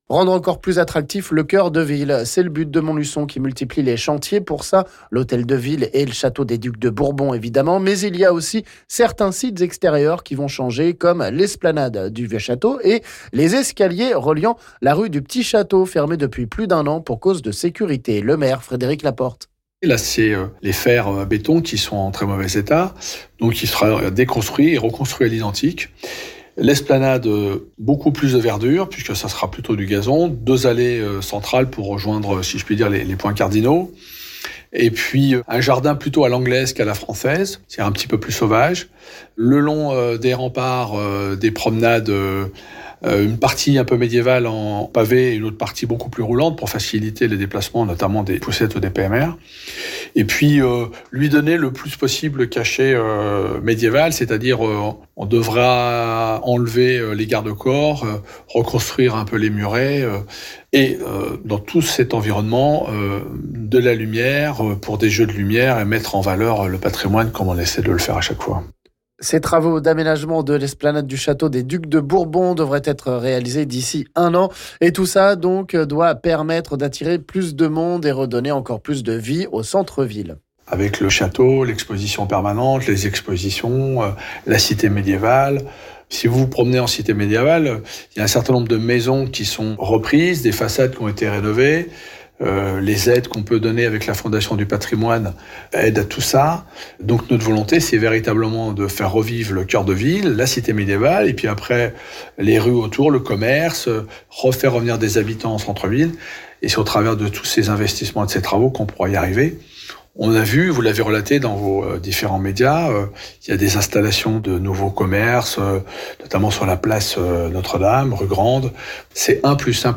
On écoute le maire Frédéric Laporte...